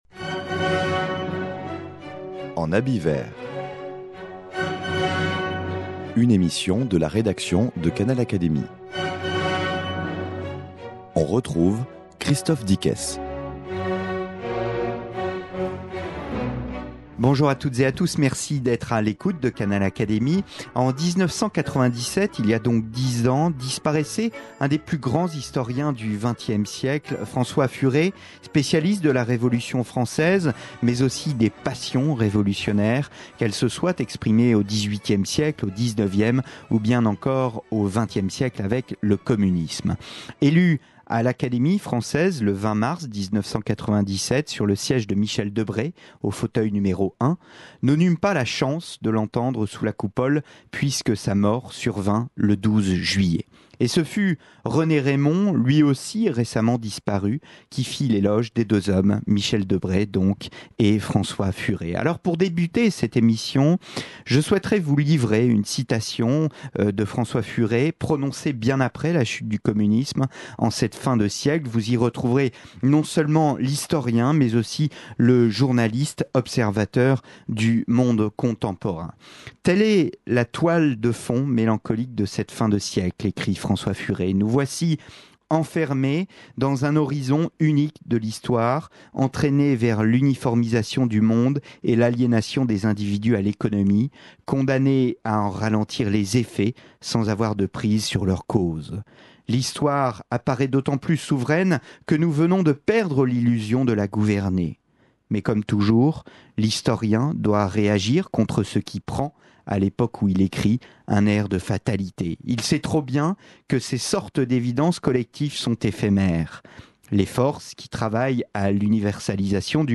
En ce dixième anniversaire de la disparition de François Furet, Mona Ozouf, qui vient de recevoir des mains d’Hélène Carrère d’Encausse le prix mondial de la Fondation Simone et Cino del duca pour l’ensemble de son oeuvre, évoque pour Canal Académie la vie de l’historien et du journaliste élu à l’Académie française en 1997.